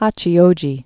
(hächē-ōjē)